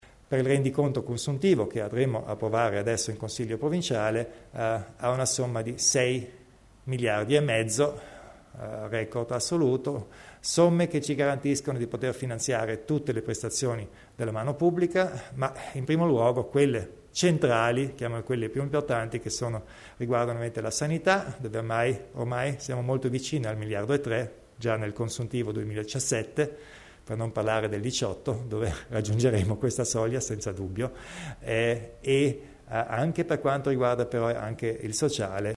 Il Presidente Kompatscher elenca le cifre più importanti per il bilancio provinciale